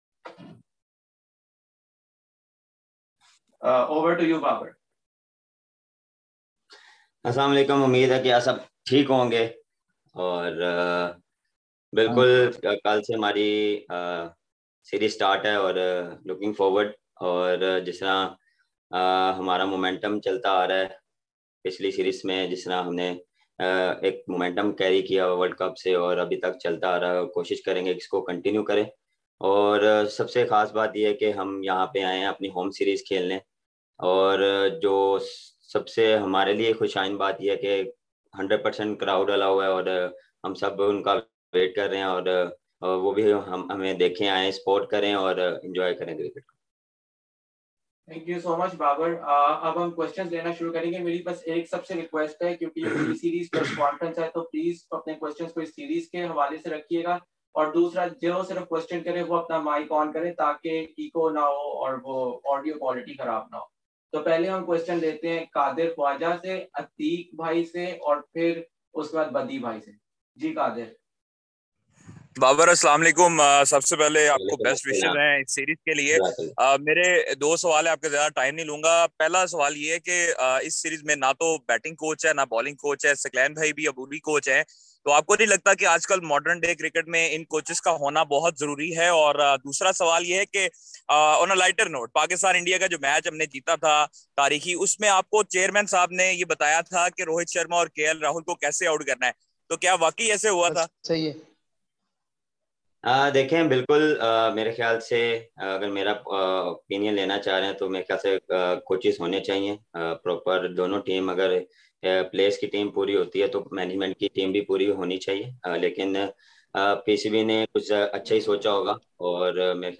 Babar Azam holds virtual pre-series media conference
Pakistan captain and world’s highest-ranked white-ball batter Babar Azam today held his pre-series online media conference.